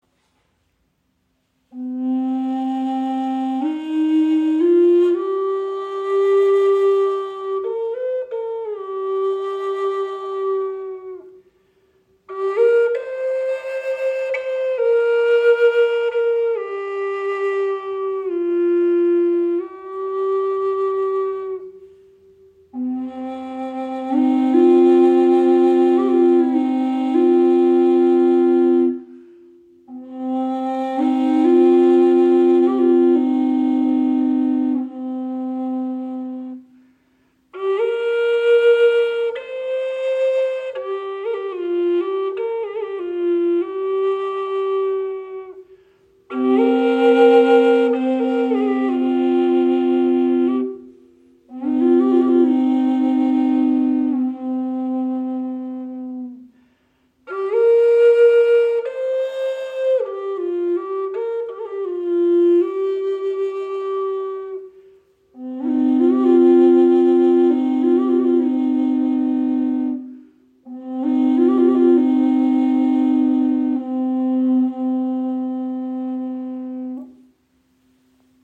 Doppelflöte in C - 432 Hz im Raven-Spirit WebShop • Raven Spirit
Klangbeispiel
Sie schenkt Dir ein wundervolles Fibrato, kann als Soloinstrument gespielt werden oder als weiche Untermahlung Deiner Musik.